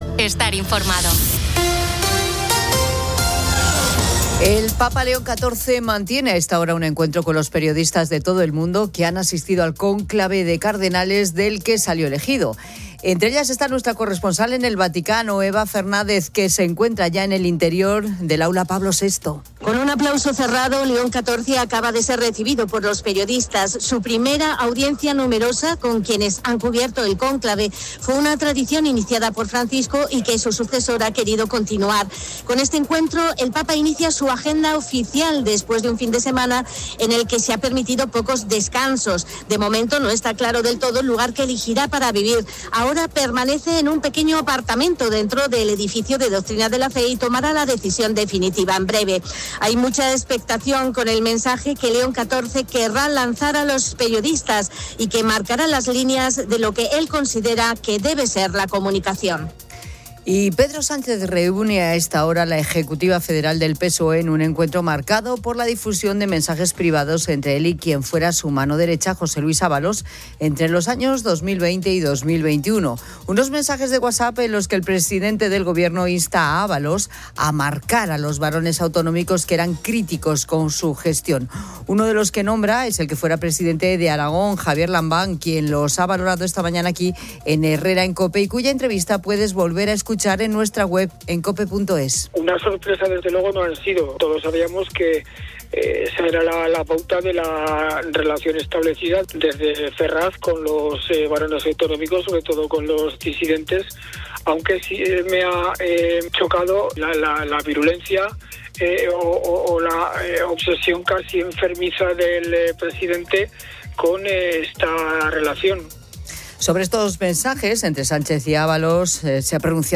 Con un aplauso cerrado, León XIV acaba de ser recibido por los periodistas.